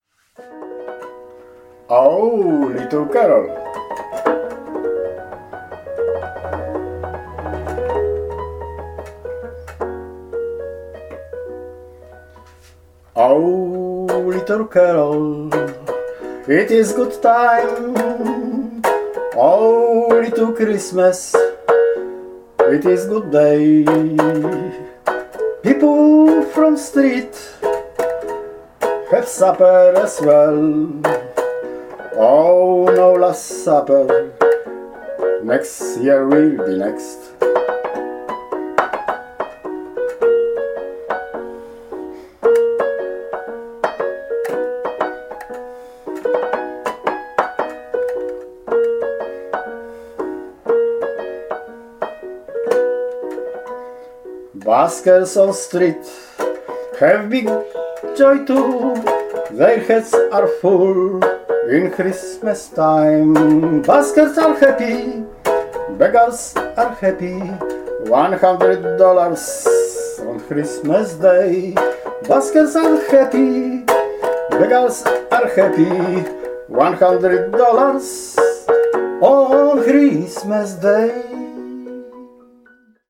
światecznej piosenki